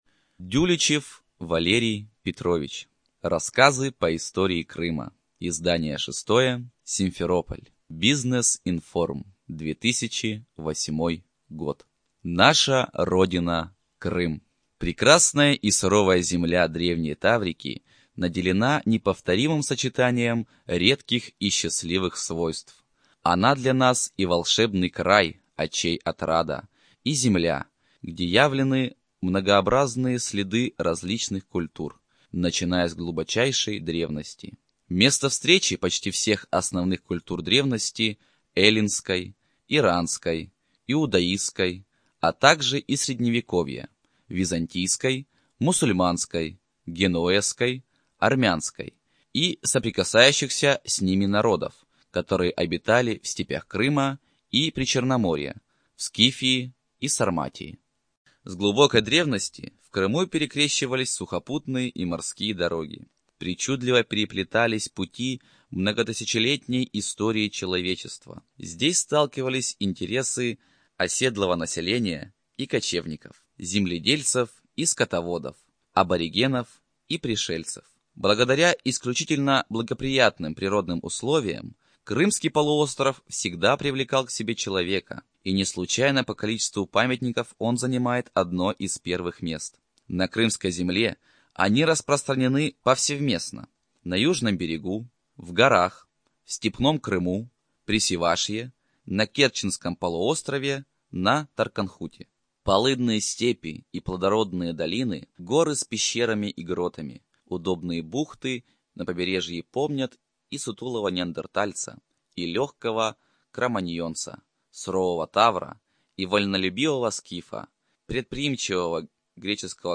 Студия звукозаписиСимферопольская библиотека для слепых и слабовидящих